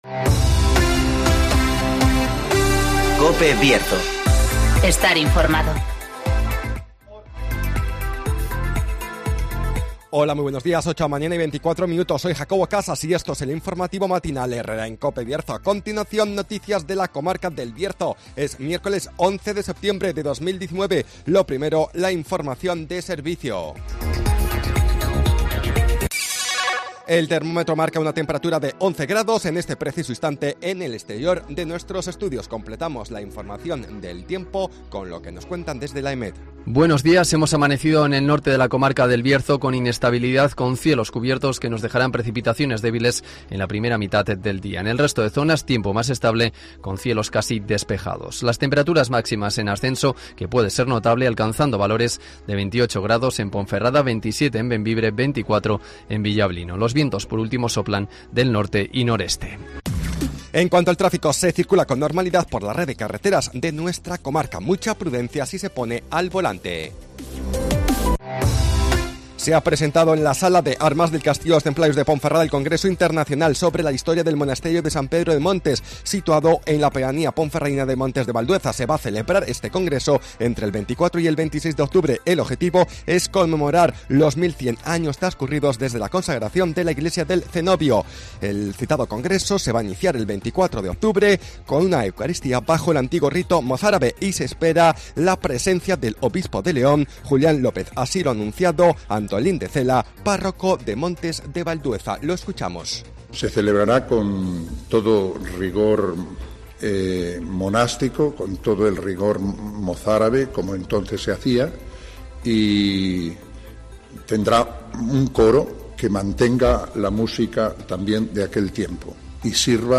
INFORMATIVOS
-Conocemos las noticias de las últimas horas de nuestra comarca, con las voces de los protagonistas